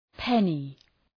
Προφορά
{‘penı}